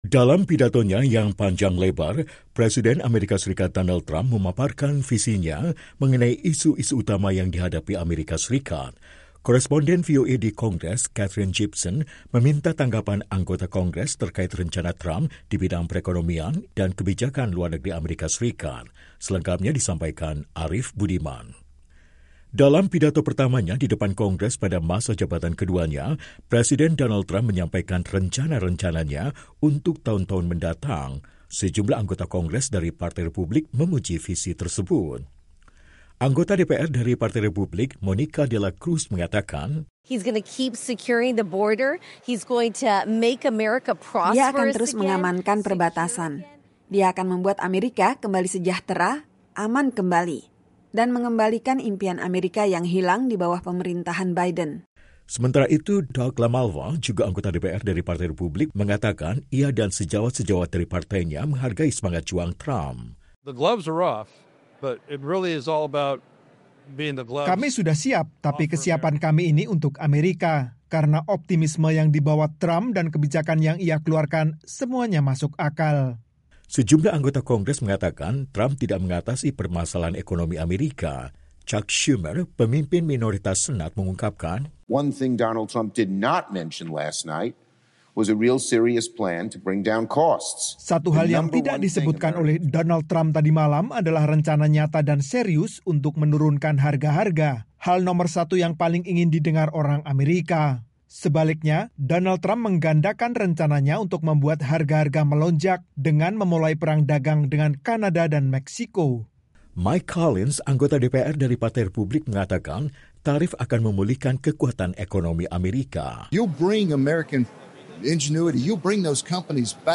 Sejumlah anggota Kongres menyampaikan tanggapan mereka terkait rencana Trump di bidang perekonomian, imigrasi, dan kebijakan luar negeri AS. Tim VOA melaporkan.